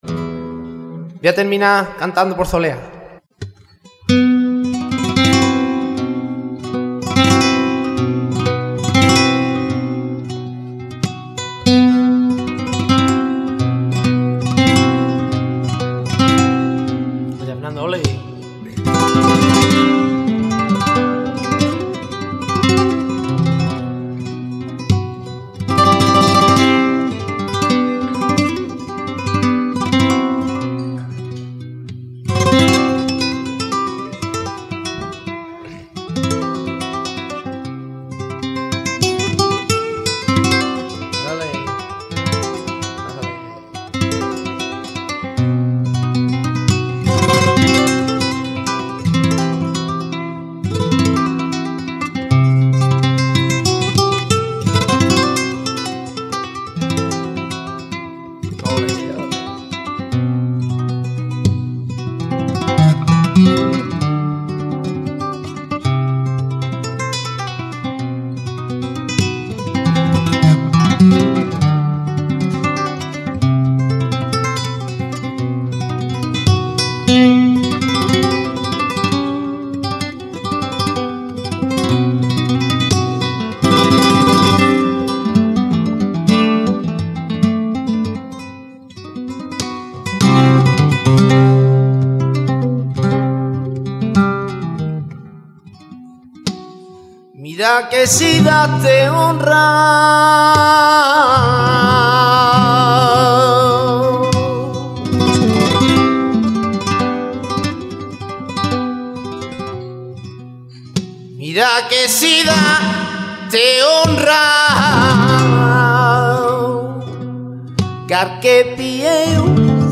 flamenco cantaor
Soleá
Guitarra
Uniek is de kracht en het bereik van zijn stem waarmee hij het publiek keer op keer op handen krijgt.
solea.mp3